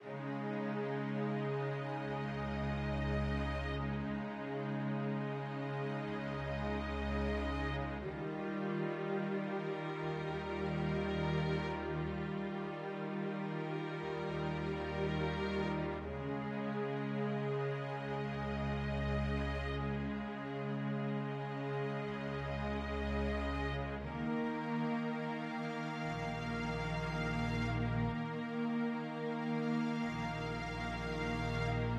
小提琴管弦乐4
Tag: 120 bpm Classical Loops Violin Loops 5.38 MB wav Key : Unknown